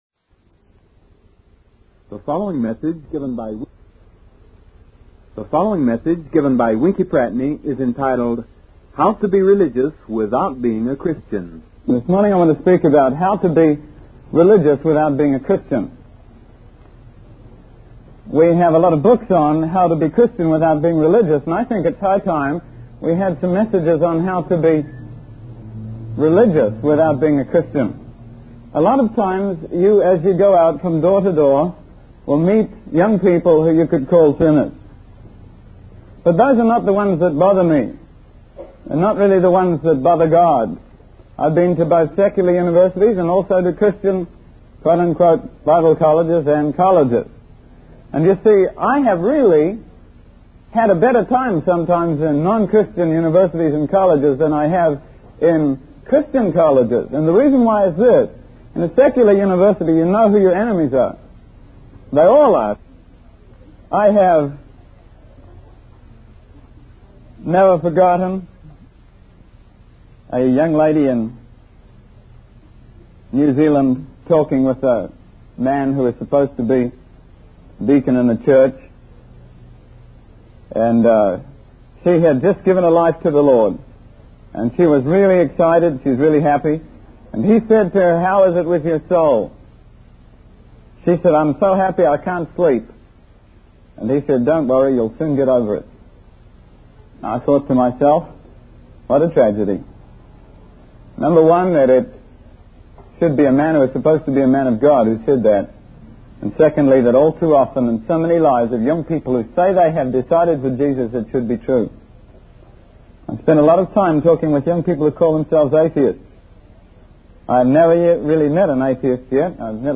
In this sermon, the preacher discusses the parable of the sower, which is repeated three times by Jesus. He identifies three kinds of counterfeit conversion - the religion of fear, the in-crowd, and the carnal Christian - and contrasts them with genuine Christianity. The preacher emphasizes the importance of understanding the motives behind one's faith and warns against superficial or insincere conversions.